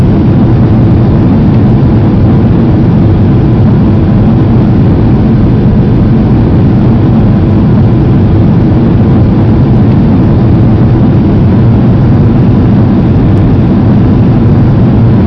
Add aft wing sounds